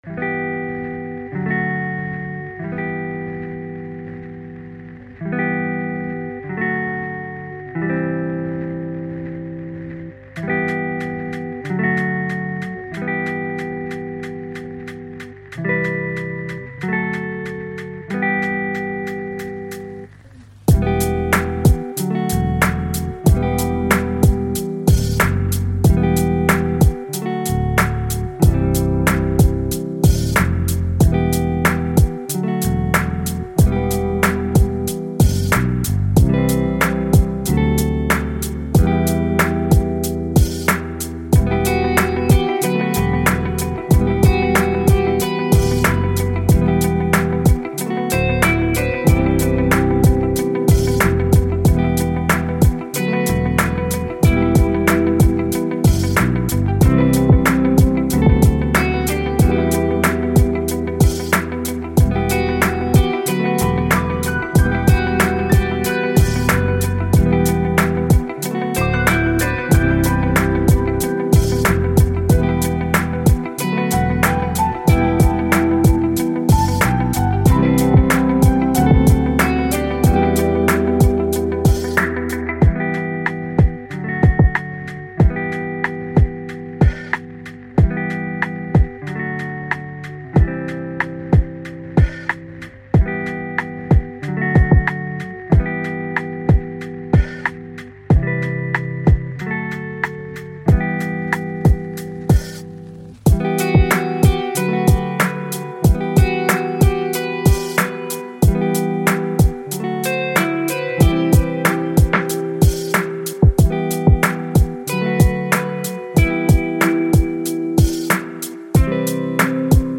Винтажная музыка для творческого вдохновения